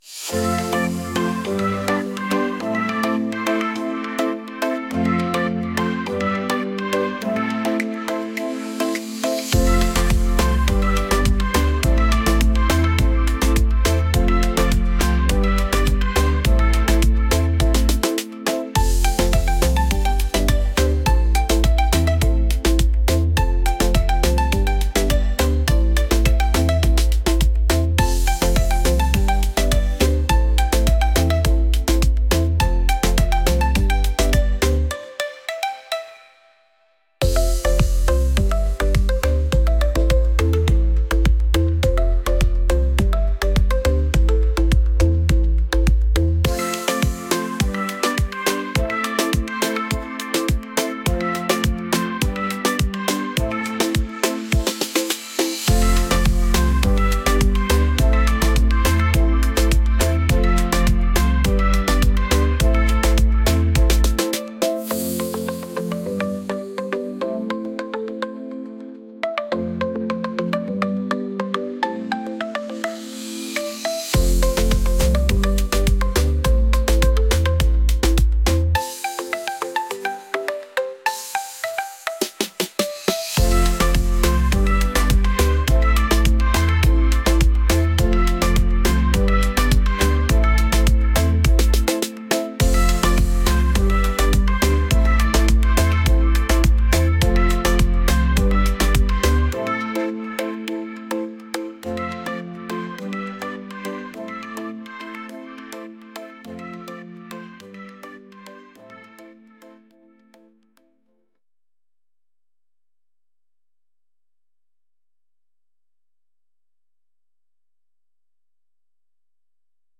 pop | catchy